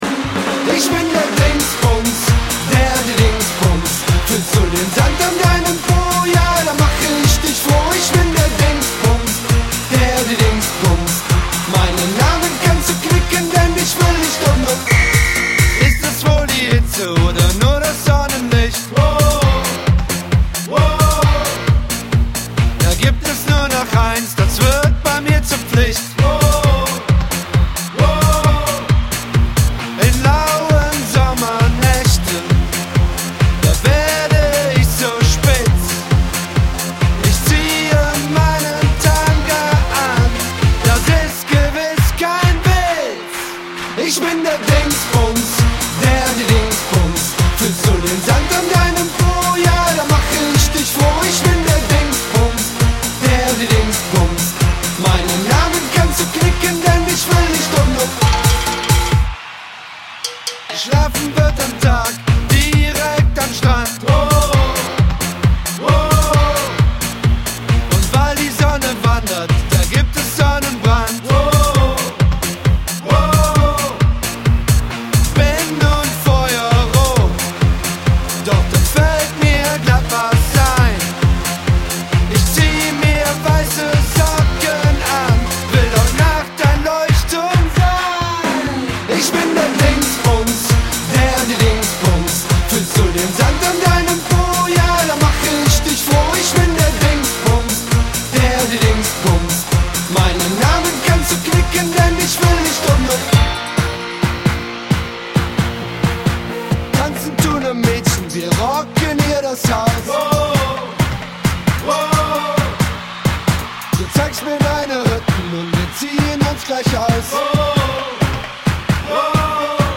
Zu jedem Party-Drink gehört der passende Party-Song!